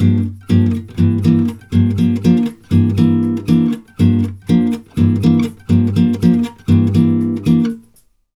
Index of /90_sSampleCDs/USB Soundscan vol.16 - Brazil Bossa [AKAI] 1CD/Partition B/09-120 G MIN
120GTR GM 02.wav